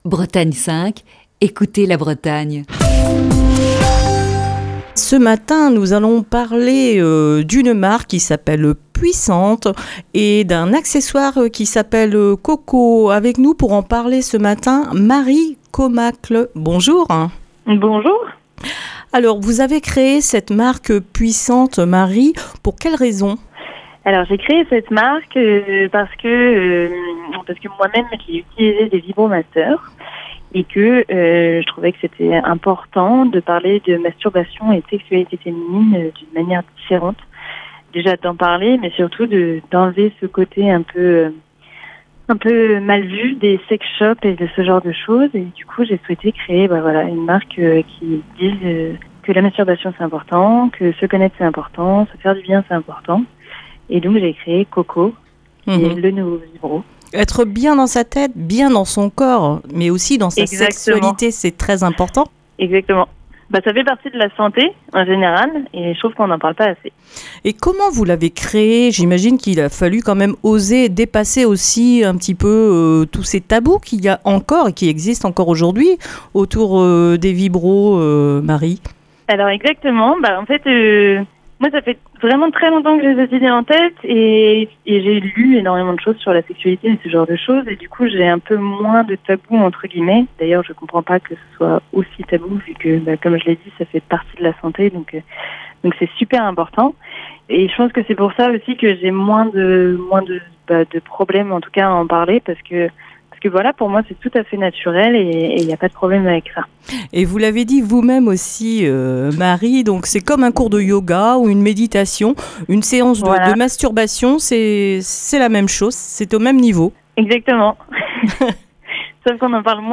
Émission du 11 janvier 2021. Dans le coup de fil du matin de ce lundi